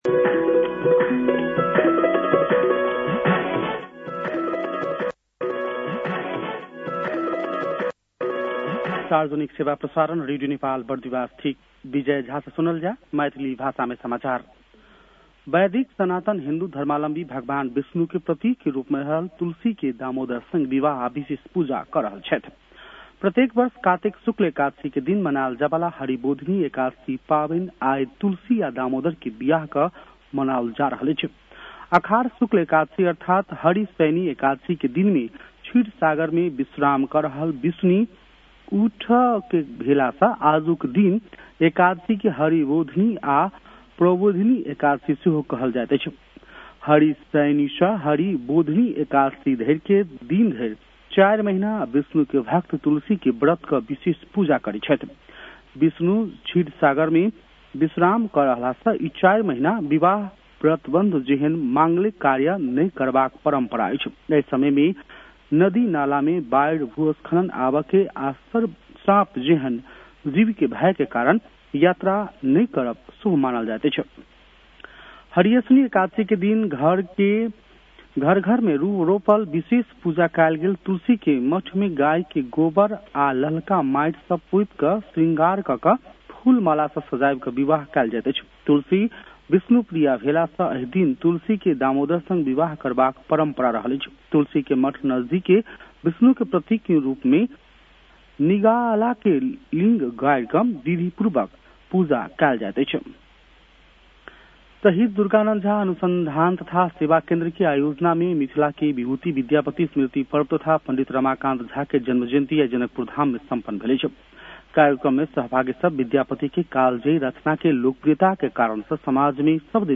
मैथिली भाषामा समाचार : १५ कार्तिक , २०८२